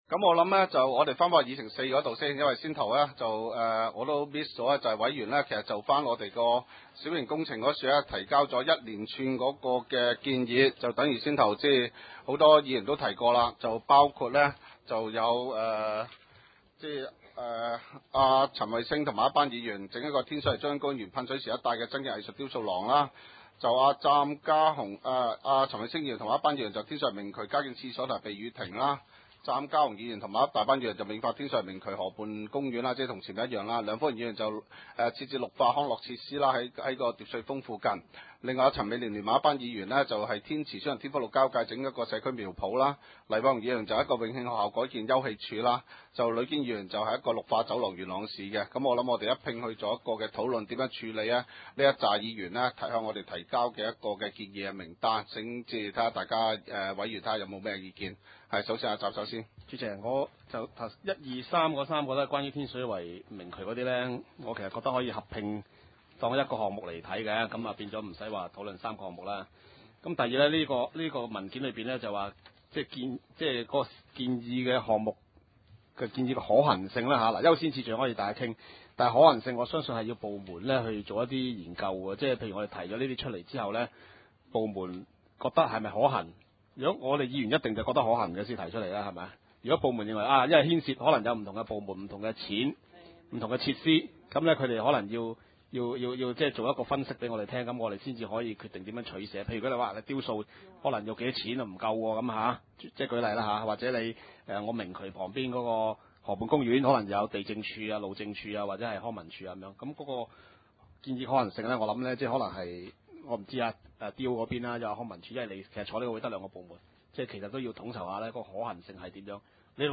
點： 元朗區議會會議廳